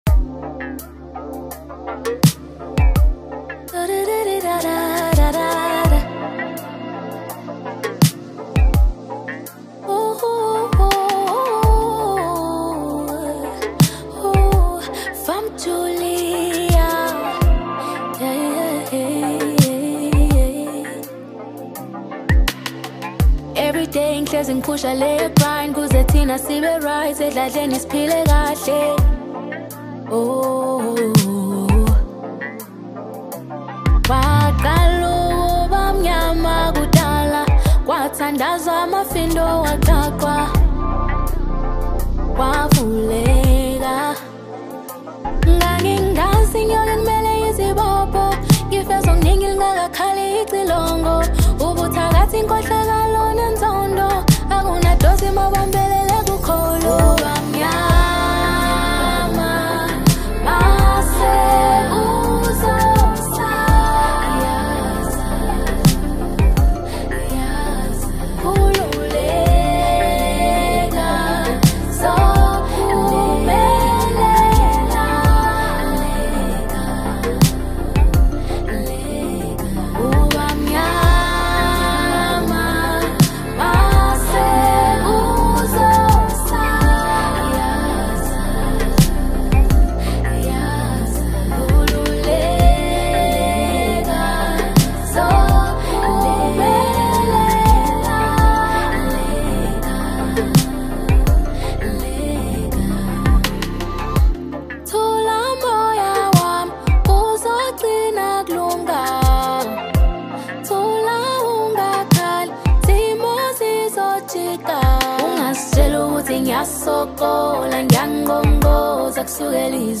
AFRO-POP Apr 07, 2026